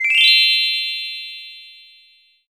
get_item.mp3